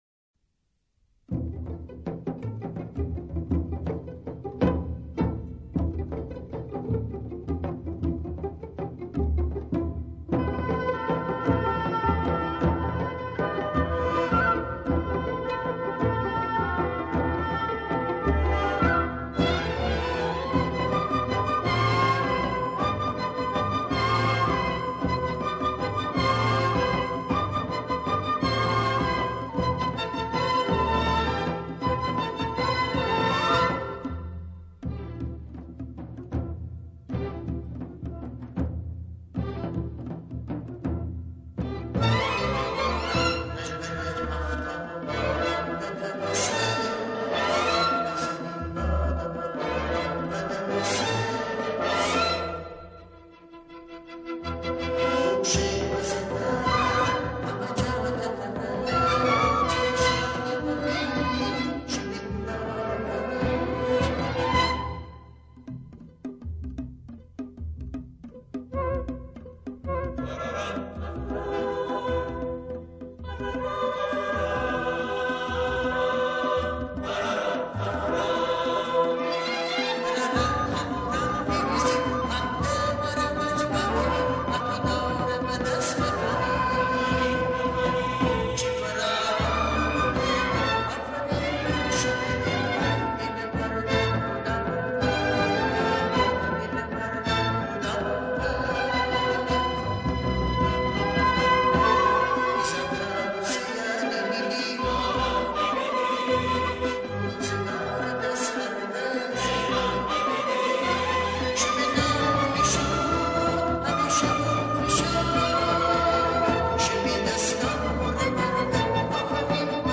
همخوانی شعری به گویش گیلکی